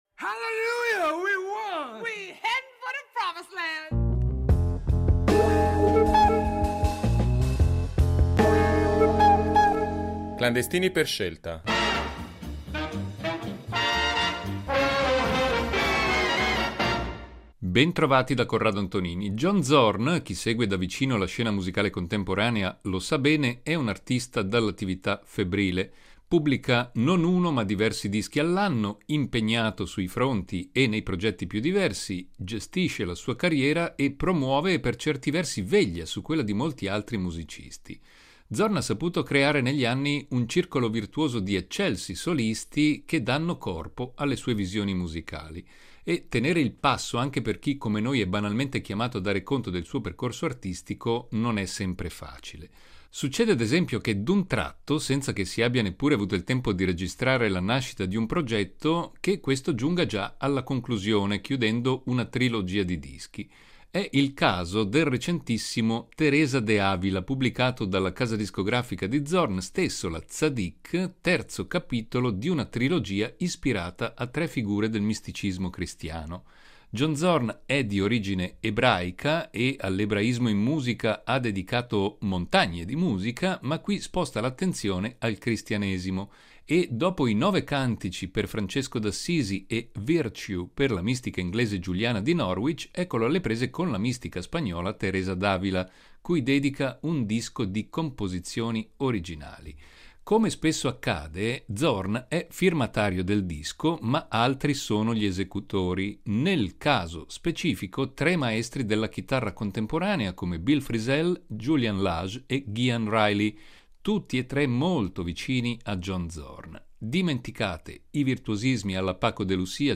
sassofonista e cantante jazz svizzera